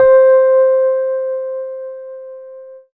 Rhodes_C4.wav